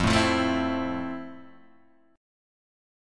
E#maj7 chord